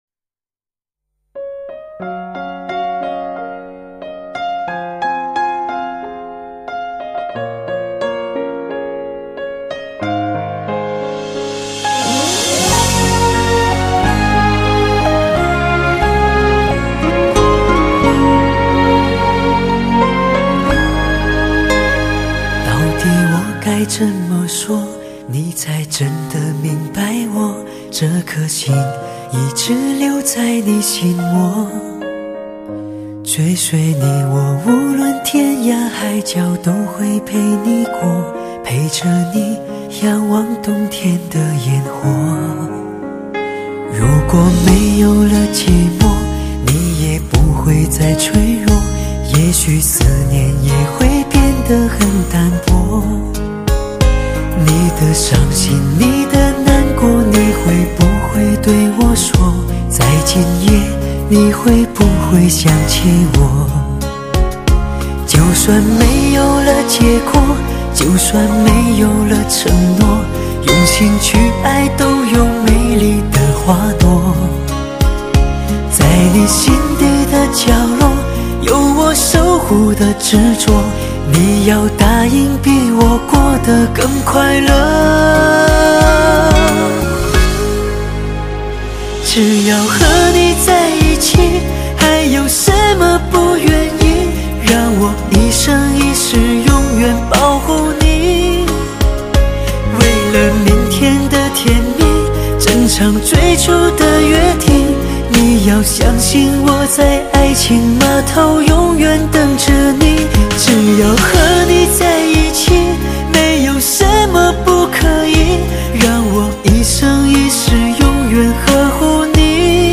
经典源式情歌